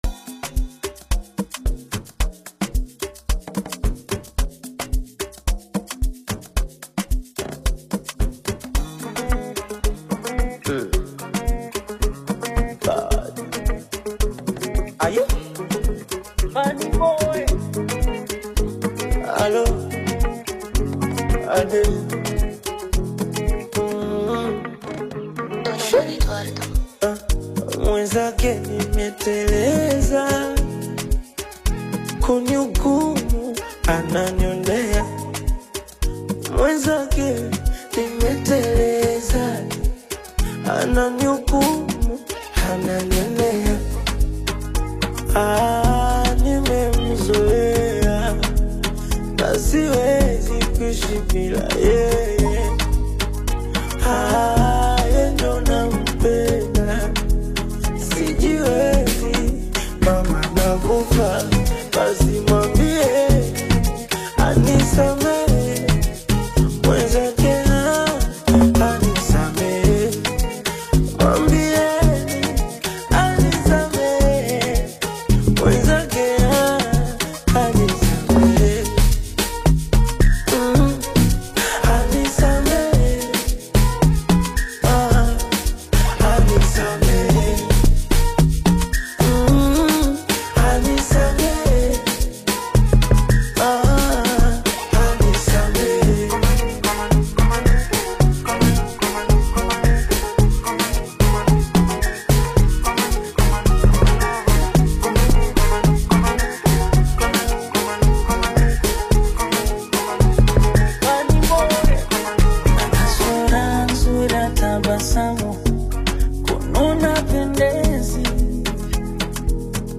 Tanzanian bongo flava recording artist
African Music